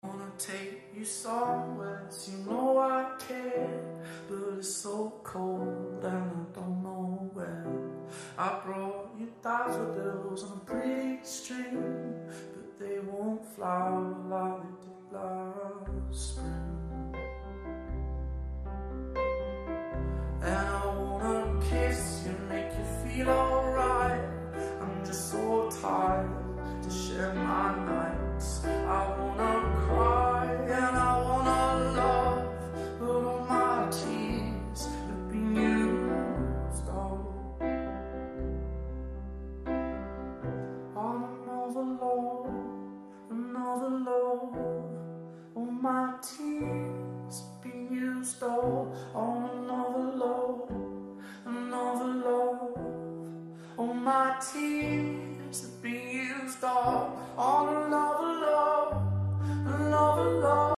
#8daudio